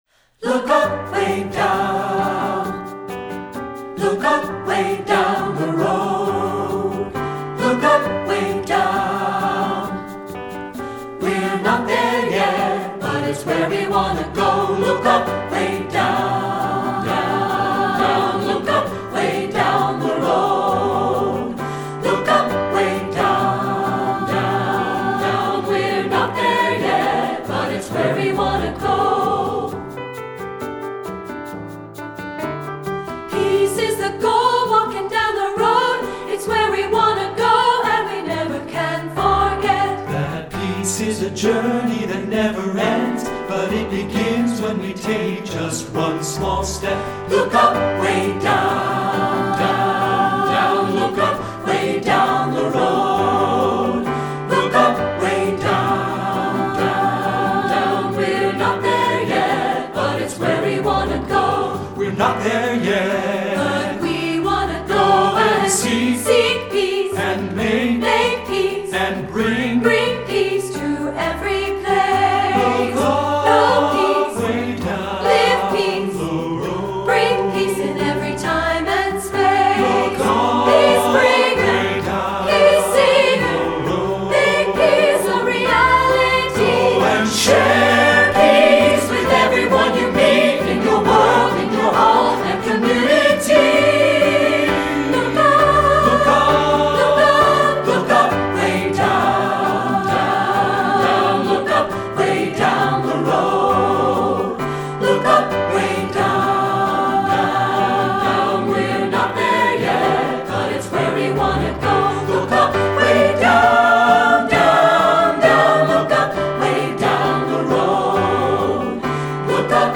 secular choral
SATB recording